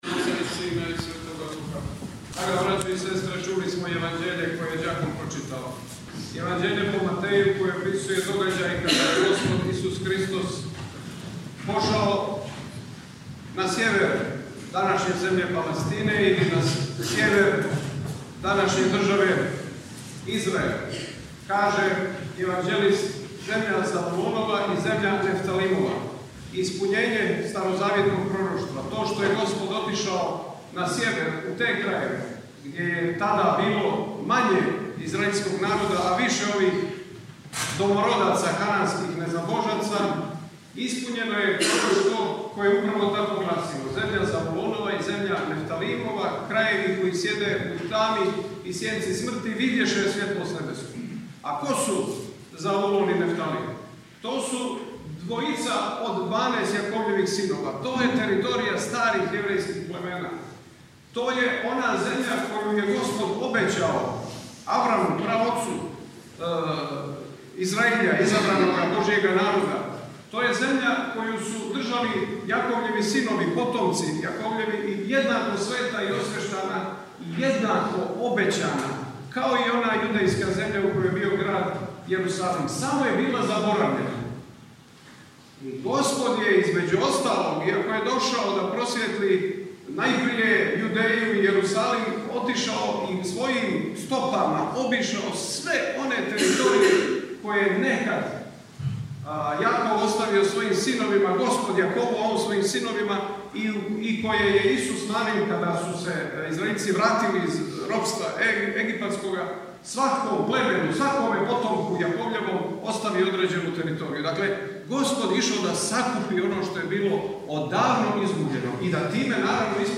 Звучни запис беседе